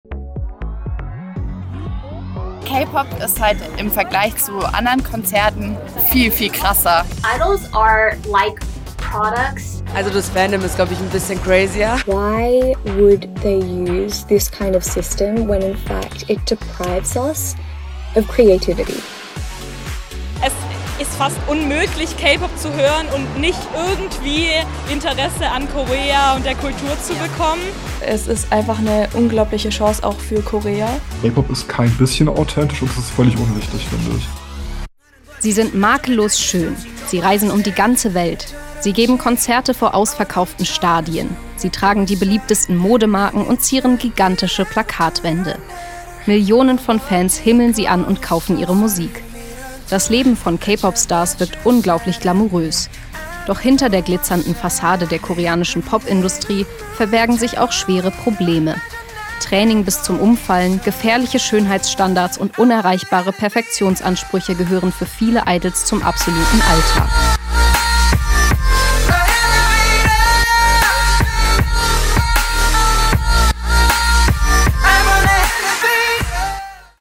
Radio Micro-Europa, der Tübinger Campusfunk: Sendung (698) „K-Pop: Ein Medienphänomen, Teil 3 – Schattenseiten“ am Mittwoch, den 22. April 2026, 17-18 Uhr im Freien Radio Wüste Welle 96,6 – Kabel: 97,45 Mhz, auch in der Mediathek.